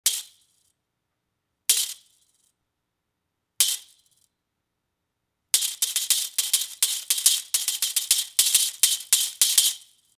ok irrelevant to wat im posting... this interesting instrument makes an insteresting sound which happens tt MUSE's drummer, dominic uses in his recording n live! hahahha happen to know it while finding info online.. no wonder i tot i saw a hihat-lookalike instru on his left side(Left hander drumset setting).. i tot he had put another hihat there which made no sense to me at the concert.. keke.. lalala